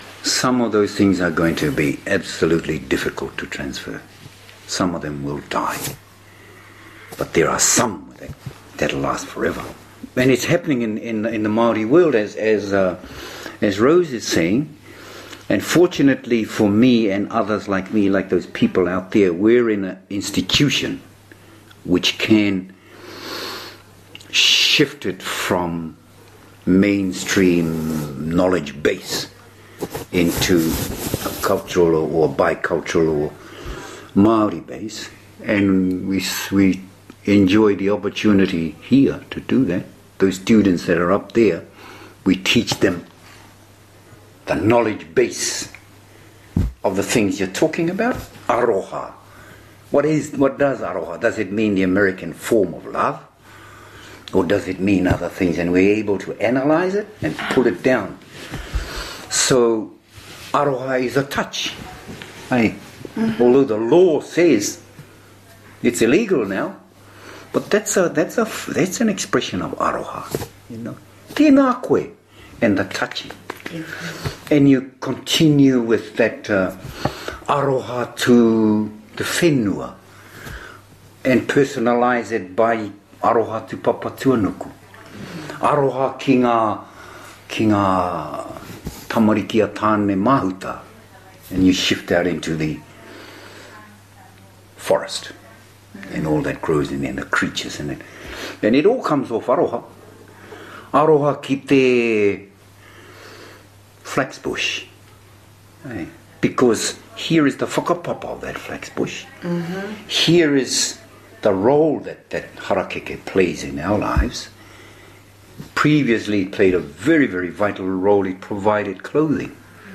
Indigenous Language Conversations on Education September 27, 2011 5:33 PM / Leave a comment Click here to listen to English conversations on education from Maori and Gaelic speakers involved with language revitalization (left click to listen, right click and ‘save target as…’ to download)